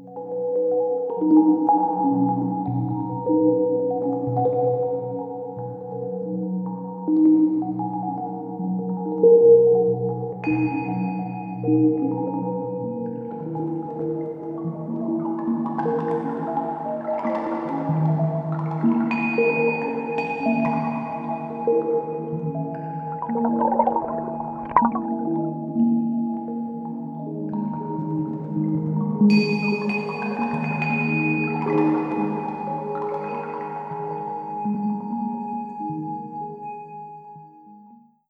Exemples de sonorités du CRDL